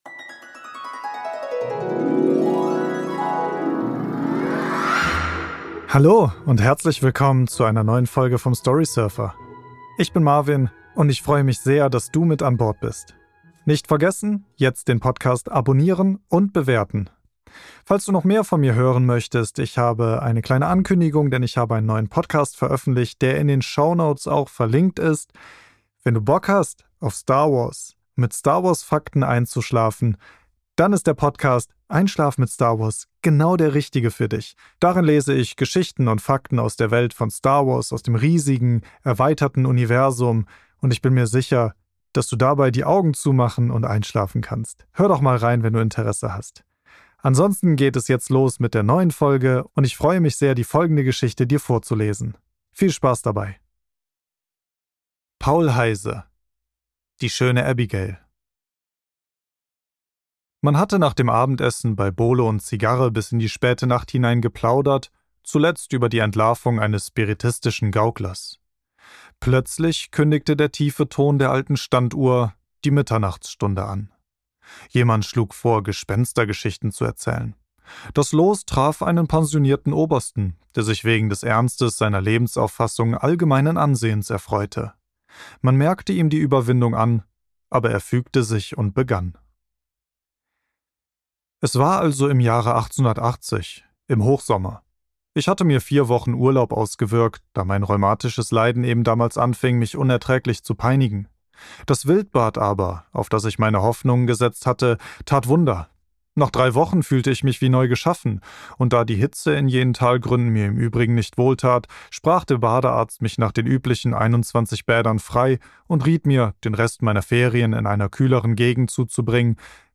Paul Heyse - Die schöne Abigail | Mystery und Romantik | Storysurfer Podcast ~ Storysurfer - Der Kurzgeschichten Hörbuch Podcast